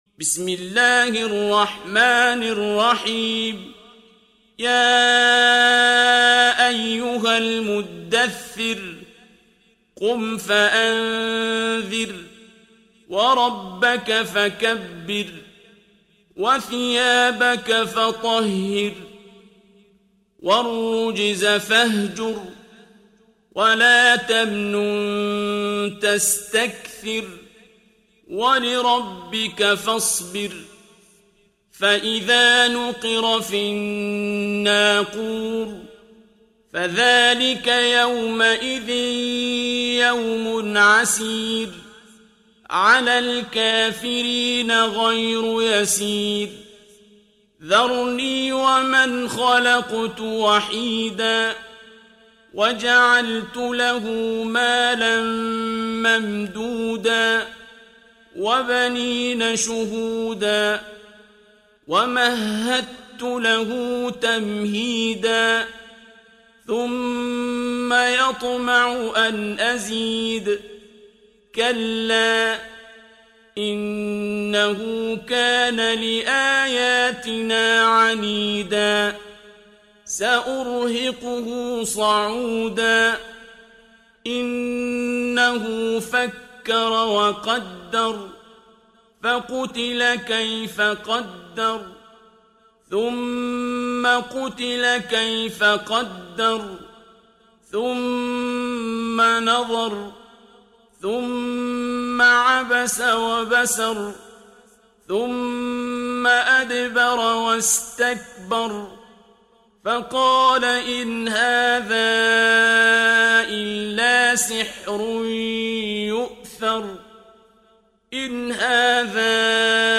Коран mp3 - сборник чтений Священного Корана - 'Абдуль-Басит 'Абдуль-Самад * – القارئ عبد الباسط عبد الصمد